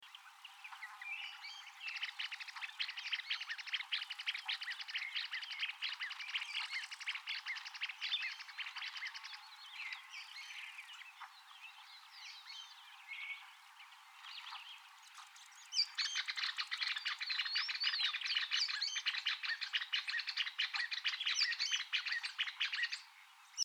Calandrita (Stigmatura budytoides)
Nombre en inglés: Greater Wagtail-Tyrant
Fase de la vida: Adulto
Localidad o área protegida: Palma Sola
Condición: Silvestre
Certeza: Observada, Vocalización Grabada